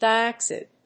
/ˌdaɪˈæpsɪd(米国英語)/